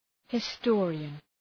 Προφορά
{hı’stɔ:rıən}